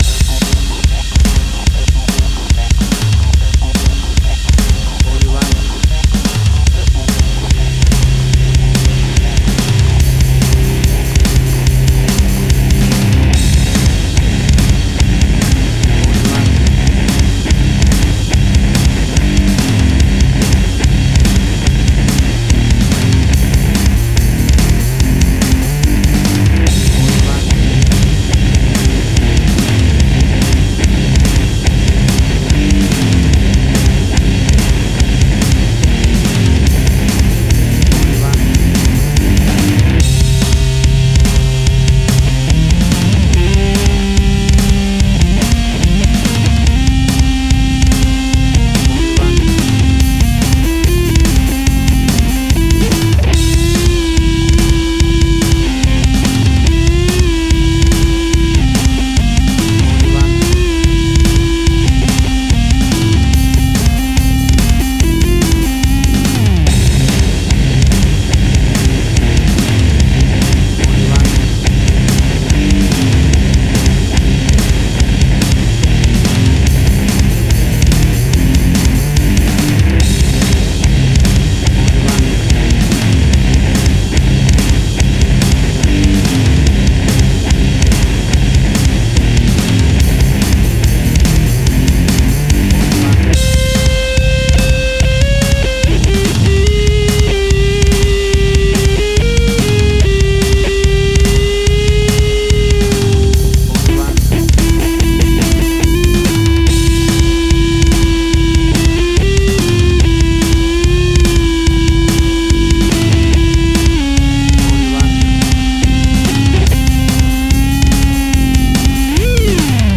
An angry and scary piece of aggressive rock metal.
Tempo (BPM): 144